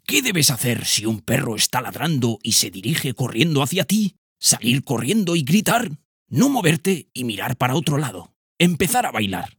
TEST PERROS DESCONOCIDOS-Narrador-10.ogg